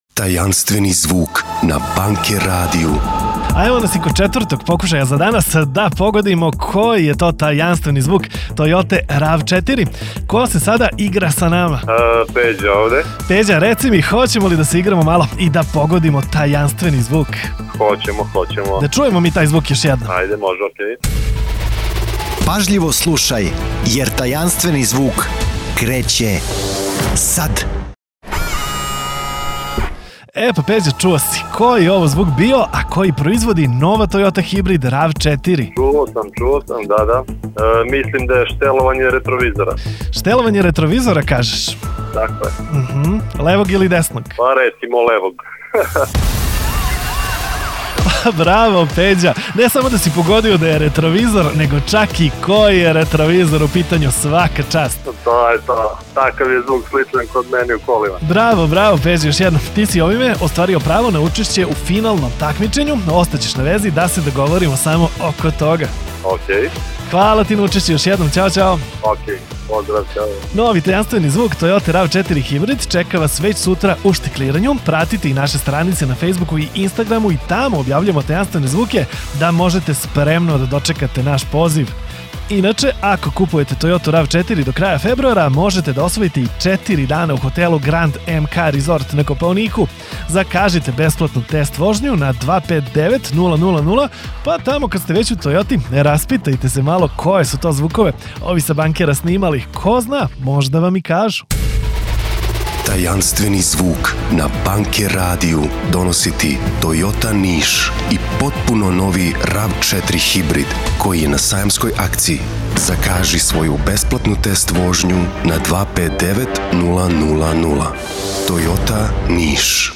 Tajanstvene zvuke koji proizvodi nova Toyota RAV4 smo snimali na licu mesta, u salonu Toyote, a onda i puštali na radiju tokom čitave nedelje, jedan dan –  jedan tajanstveni zvuk.